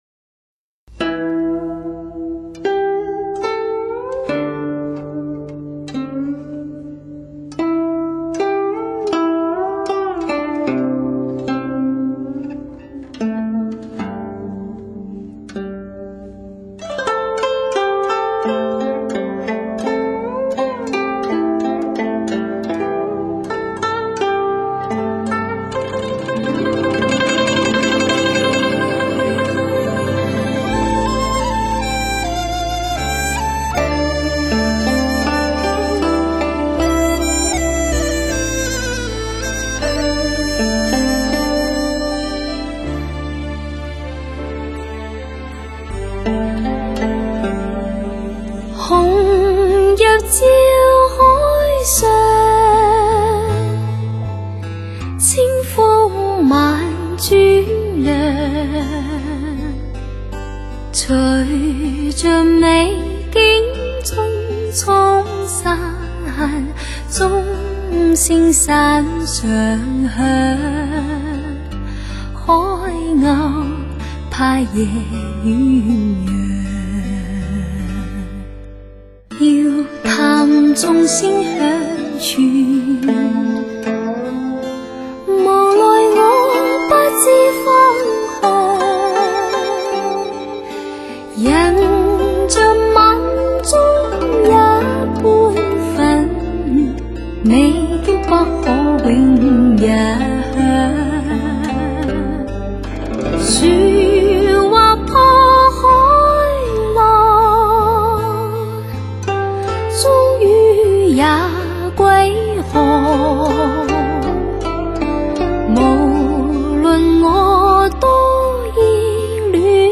冷峻与柔美对比强烈大胆创新过耳难忘。